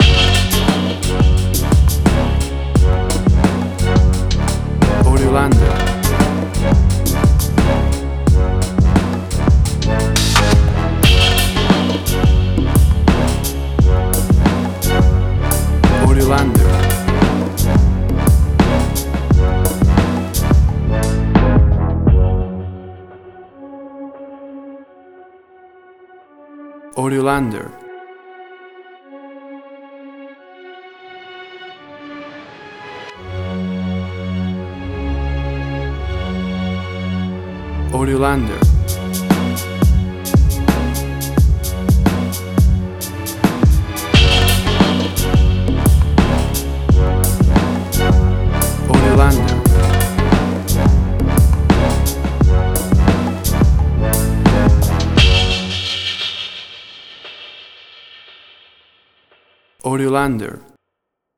WAV Sample Rate: 24-Bit stereo, 44.1 kHz
Tempo (BPM): 87